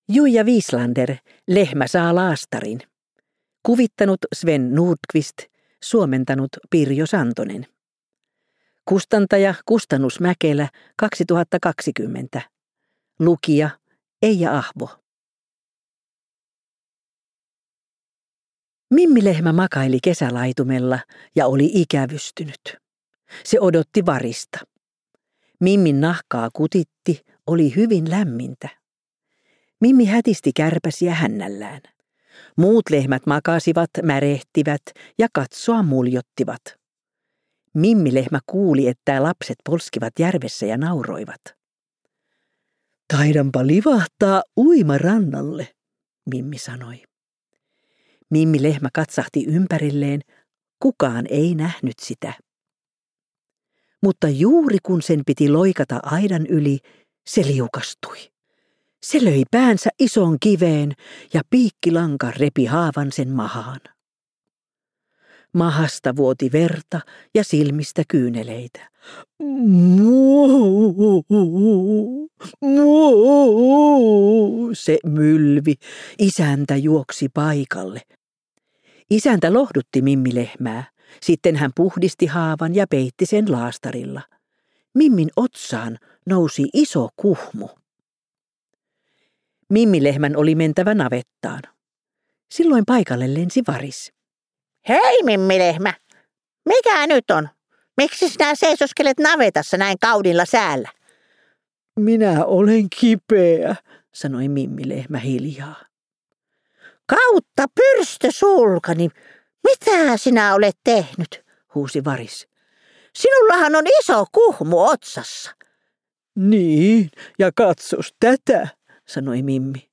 Kuunneltavissa myös äänikirjana useissa eri äänikirjapalveluissa, lukijana Eija Ahvo.